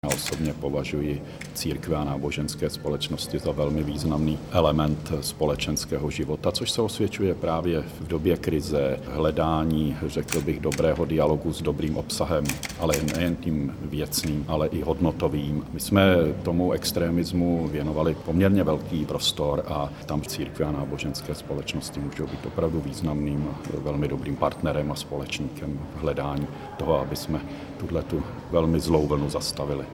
Premiér o významu církví pro českou společnost řekl: (audio MP3)